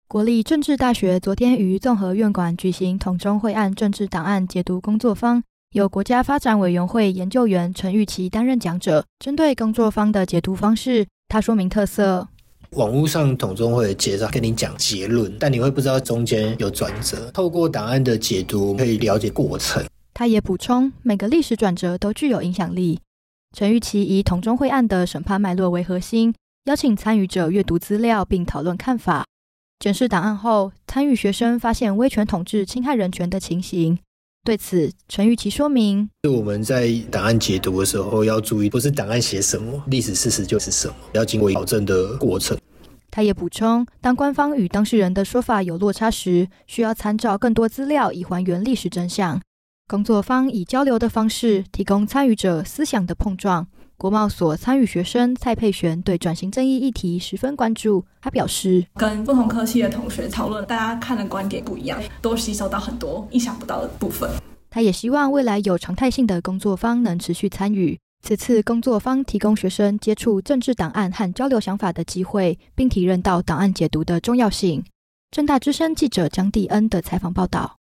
政大之聲實習廣播電台-大台北重點新聞